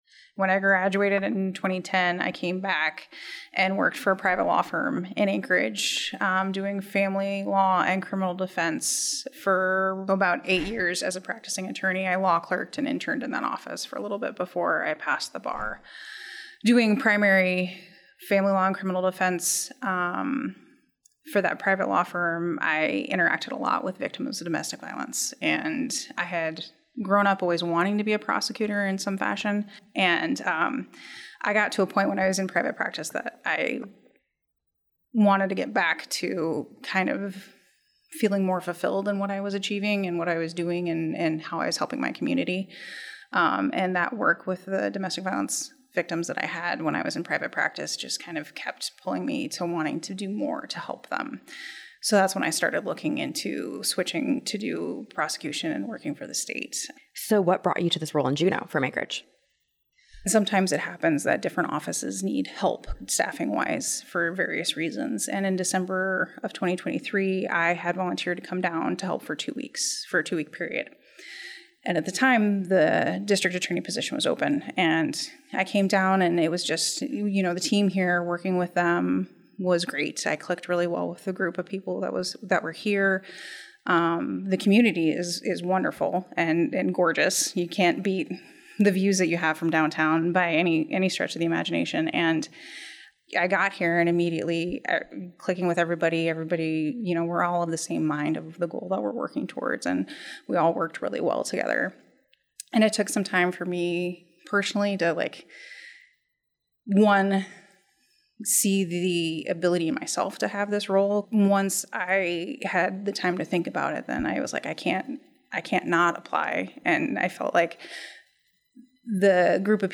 Bostick sat down with KTOO to discuss her background and plans to address these delays.